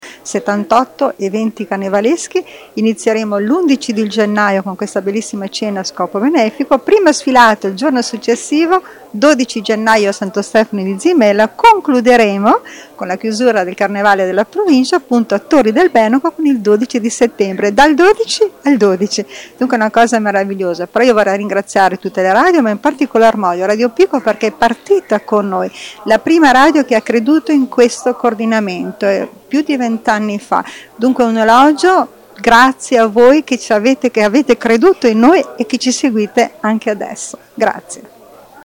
Al microfono del nostro corrispondente